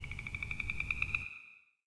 frog8.wav